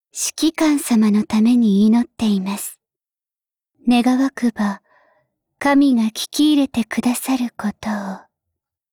（ 留言 | 贡献 ） 协议：Copyright，人物： 碧蓝航线:扶桑·META语音 您不可以覆盖此文件。